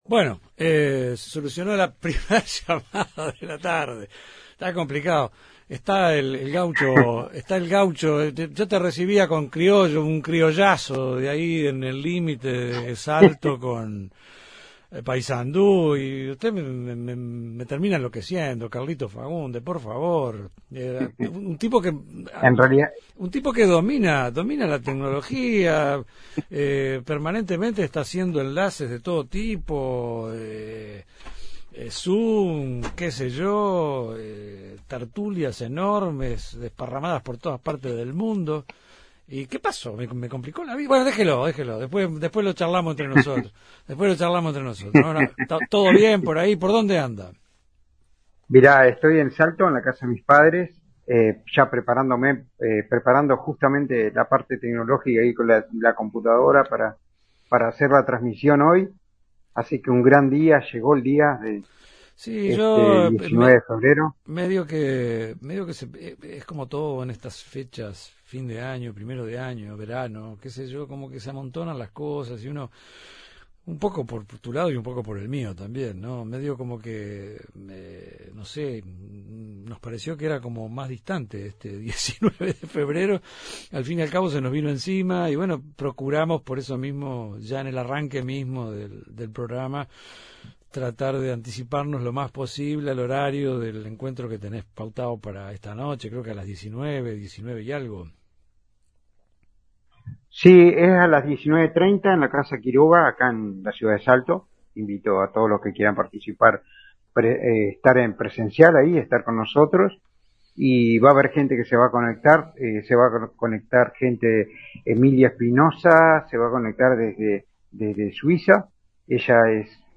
Varios panelistas